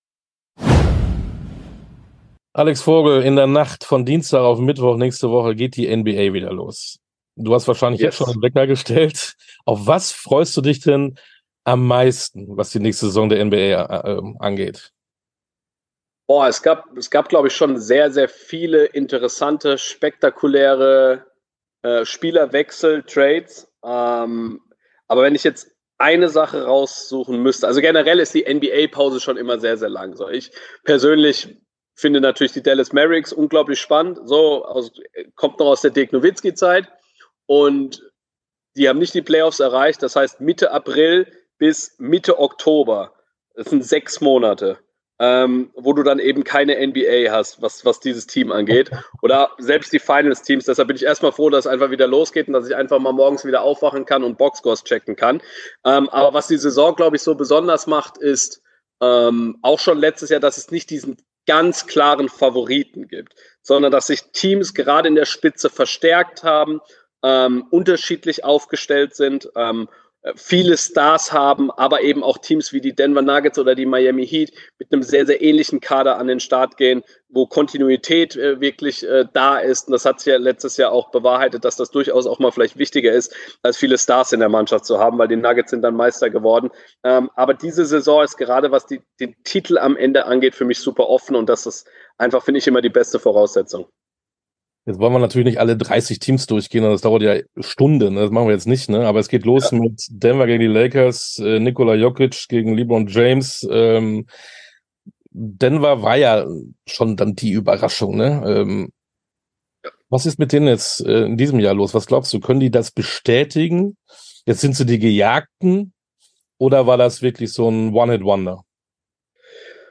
Sportstunde - Interviews in voller Länge Podcast